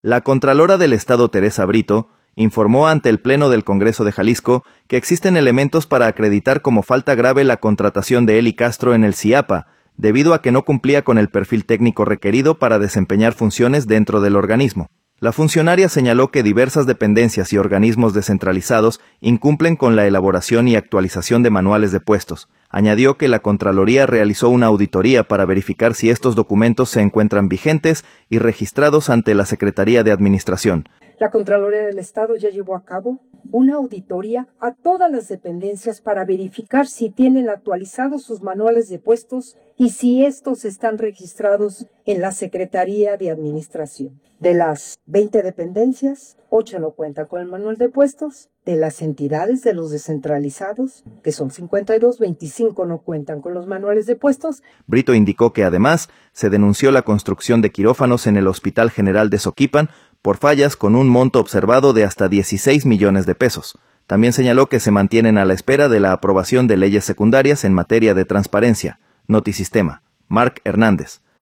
La contralora del Estado, Teresa Brito, informó ante el pleno del Congreso de Jalisco